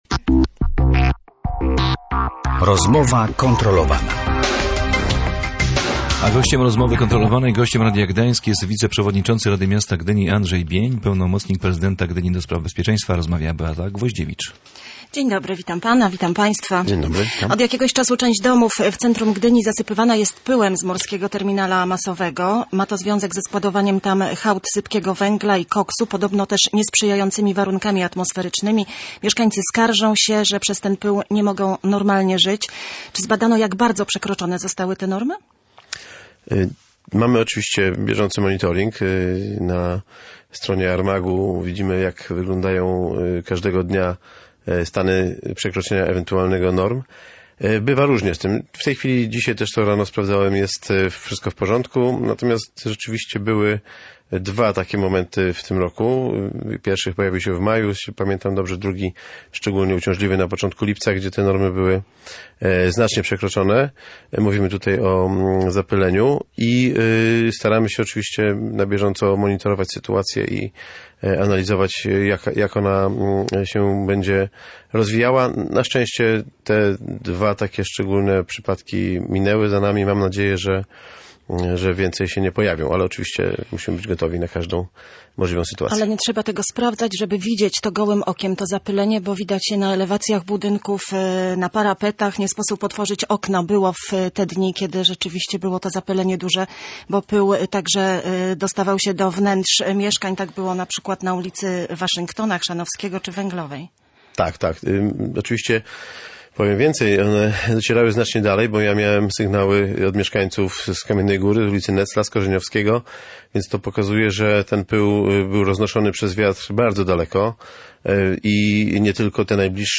Wiceprzewodniczący Rady Miasta Gdyni i pełnomocnik prezydenta Gdyni ds. bezpieczeństwa Andrzej Bień był gościem Rozmowy kontrolowanej.